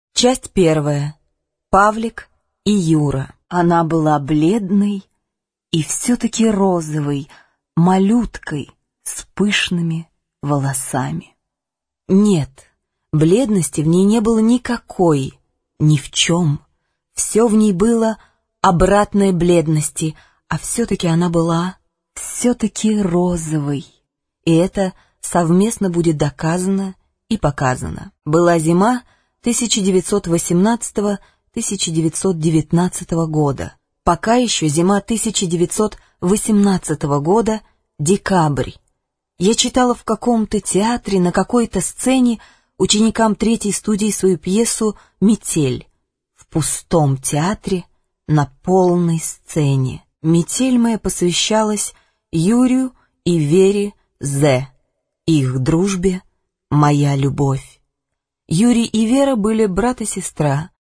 Аудиокнига Повесть о Сонечке | Библиотека аудиокниг
Прослушать и бесплатно скачать фрагмент аудиокниги